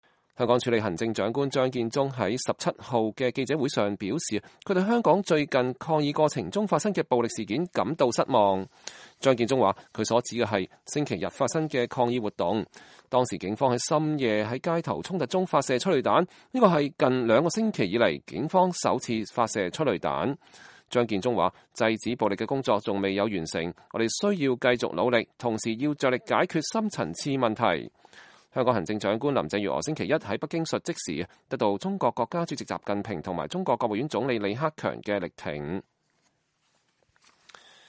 香港署理行政長官張建宗星期二（12月17日）在每週例行記者會上表示，他對香港最近抗議過程中發生的暴力事件感到失望。